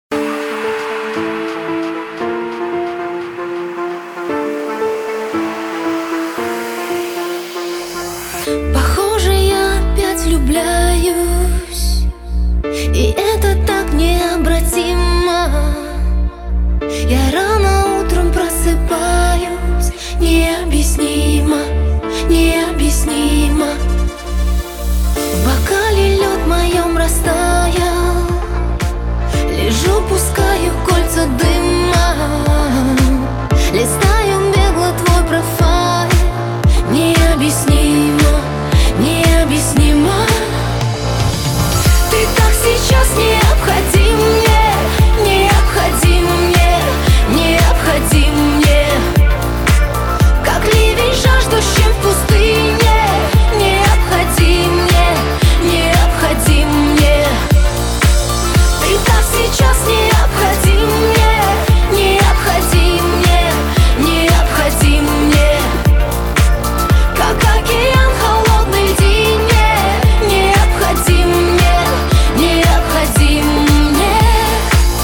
• Качество: 320, Stereo
поп
женский вокал
dance